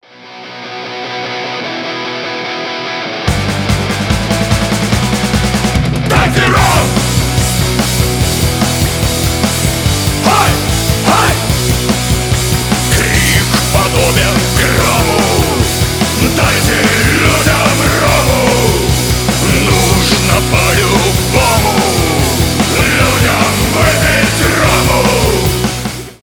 Рок рингтоны
панк-рок , бодрые